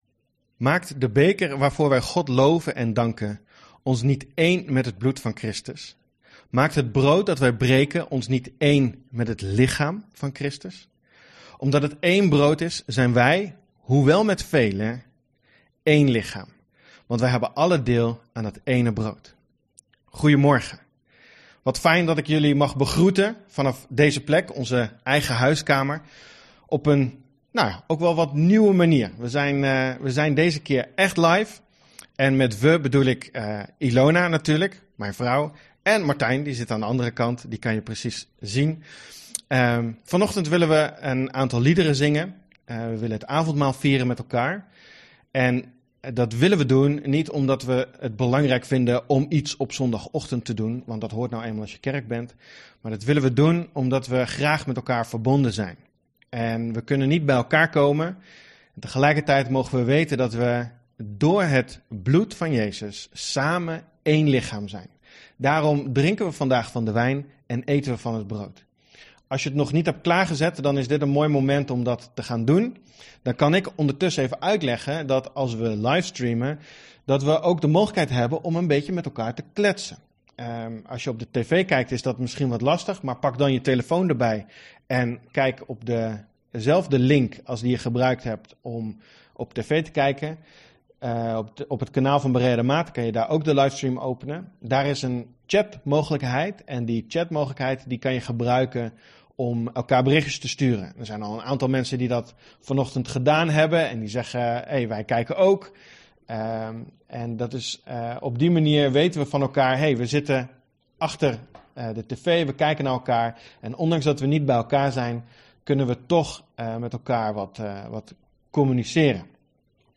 Avondmaal, audio opname van Youtube stream
Audio opname van YouTube uitzending